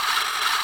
scrape loop 4.aiff